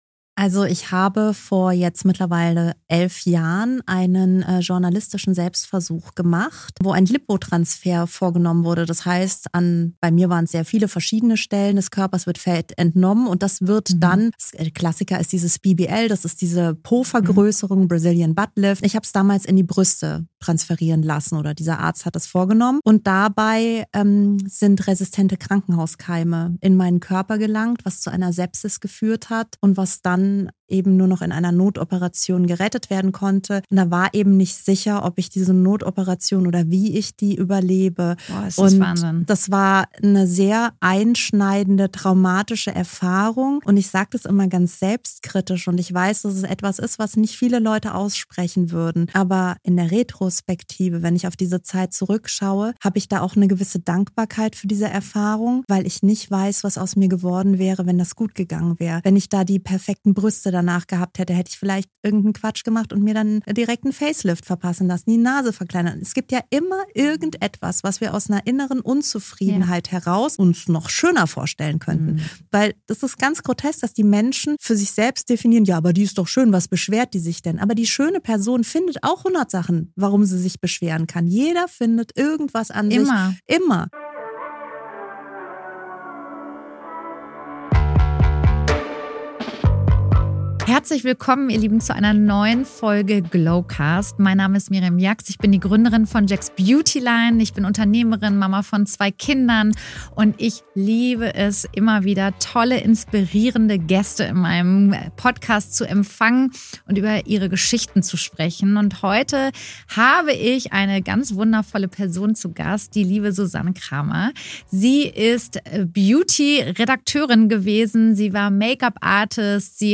Ein Gespräch über Schmerz, Heilung und die Kraft, sich selbst wieder anzusehen.